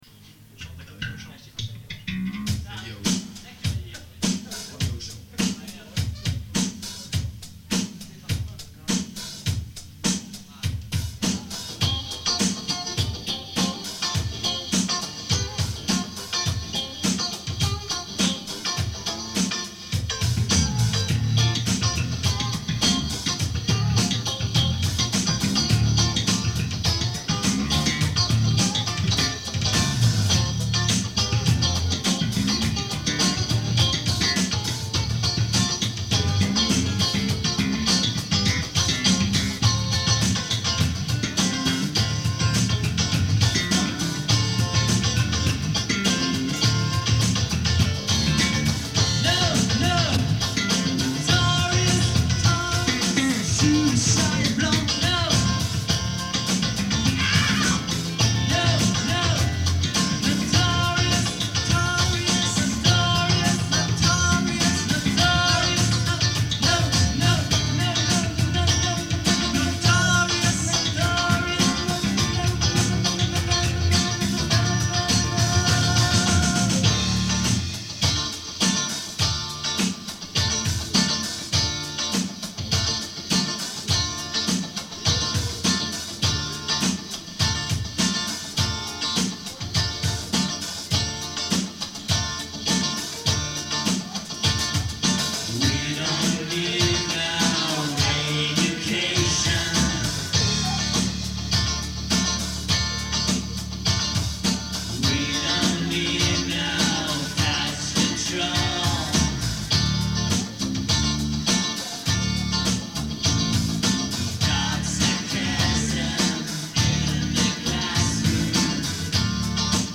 LIVE EN PUBLIC (1983 - 2020) - Un résumé...
Chant
Guitare
Claviers
Batterie
Via Brazil (St Laurent du Var) 14 Mars 1991